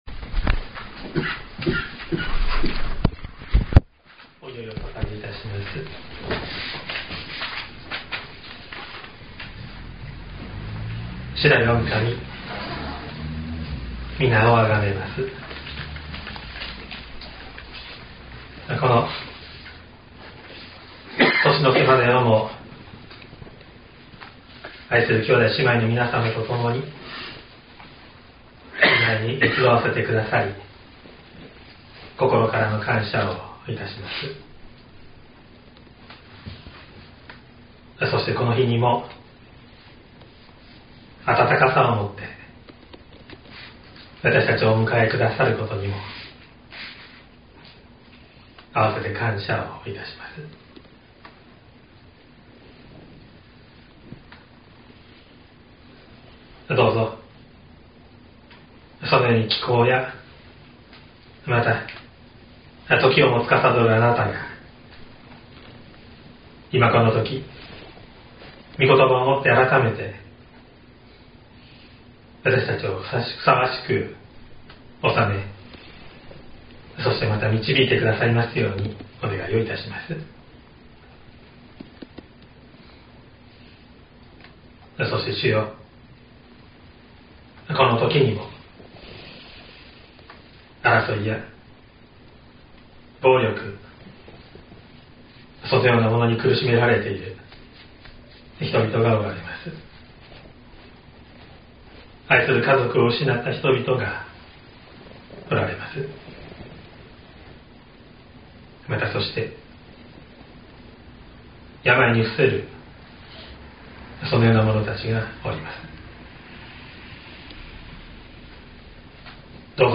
2023年12月31日朝の礼拝「信仰があなたを救う」西谷教会
説教アーカイブ。
音声ファイル 礼拝説教を録音した音声ファイルを公開しています。